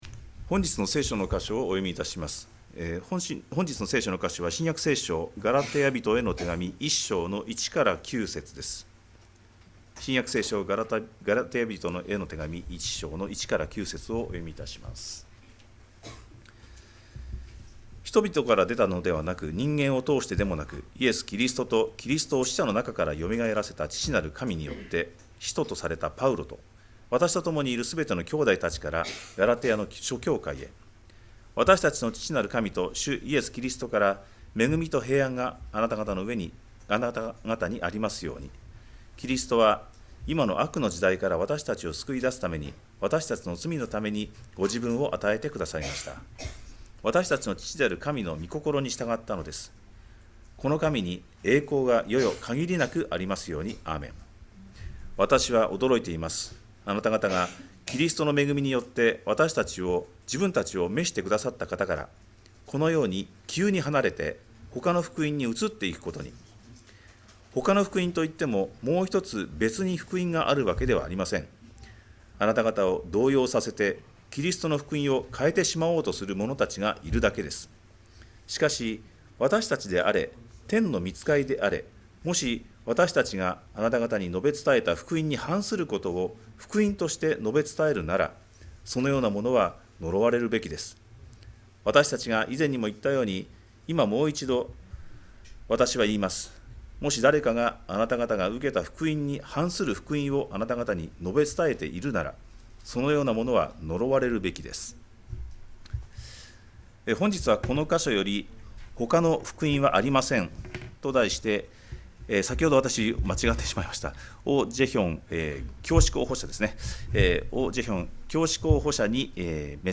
2025年5月11日礼拝 説教 「ほかの福音はありません」 – 海浜幕張めぐみ教会 – Kaihin Makuhari Grace Church